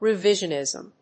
音節re・ví・sion・ìsm 発音記号・読み方
/‐nìzm(米国英語), ri:ˈvɪʒʌˌnɪzʌm(英国英語)/